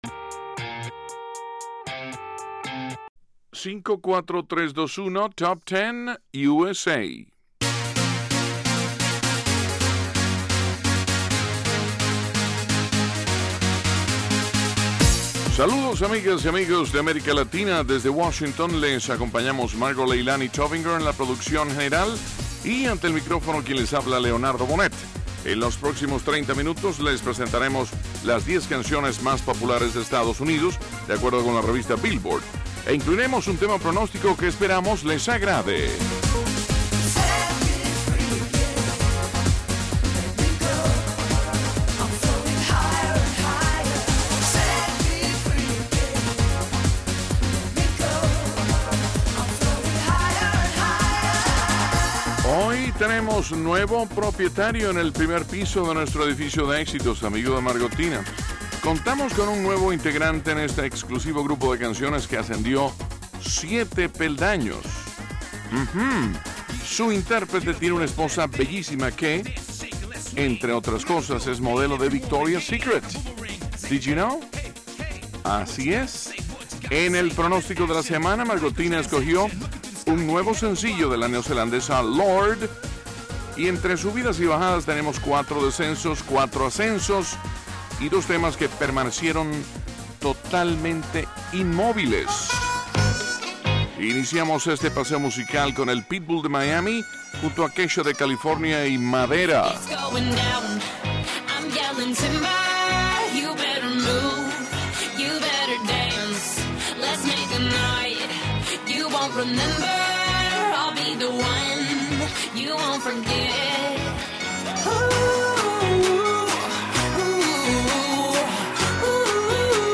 Top Ten USA es una revista musical que presenta a los más destacados artistas internacionales.